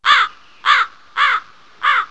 Crow Chirping 3